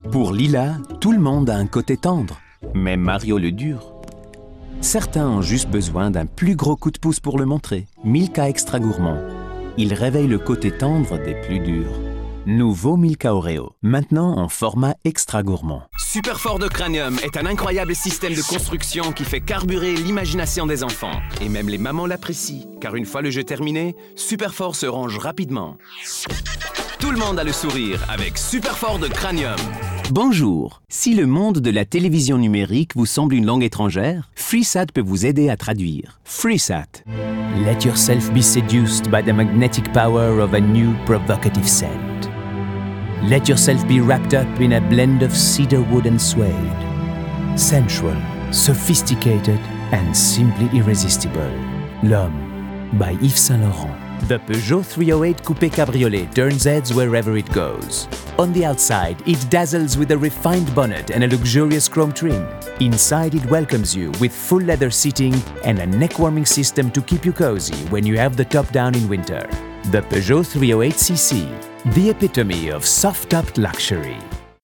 Audiobook Reel
Playing age: 30 - 40sNative Accent: French, German, International, SpanishOther Accents: French, German, Italian, Russian, Spanish
He has a versatile voice ranging from a reassuring warm baritone to youthful high-energy delivery and has an exceptional ear for accents and character voices.
He has a professionally equipped home studio for remote recording.